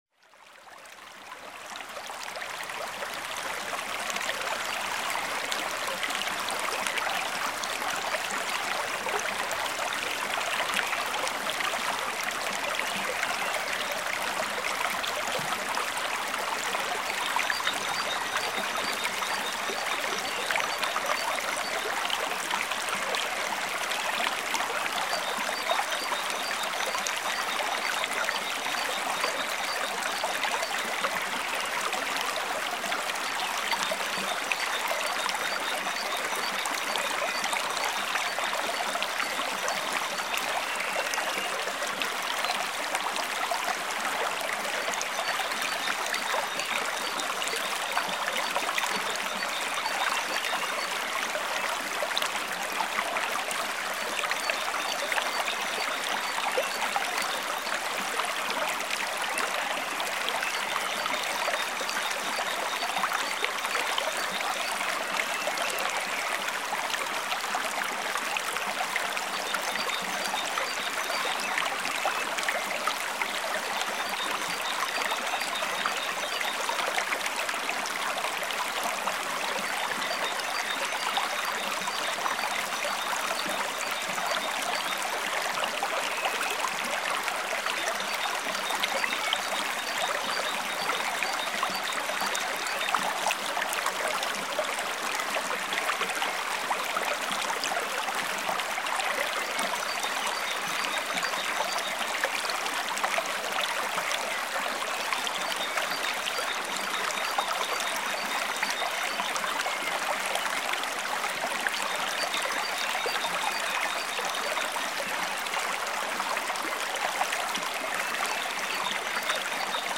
Bachrauschen